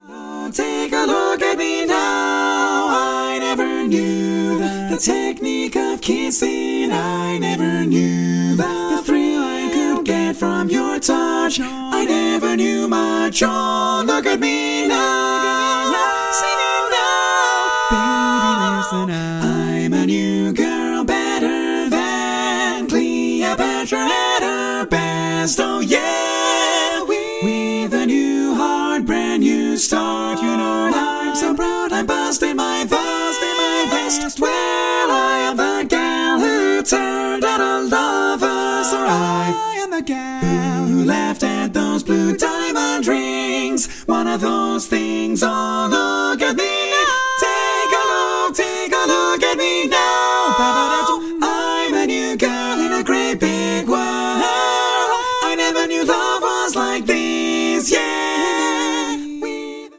Female